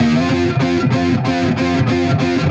Index of /musicradar/80s-heat-samples/95bpm
AM_HeroGuitar_95-F01.wav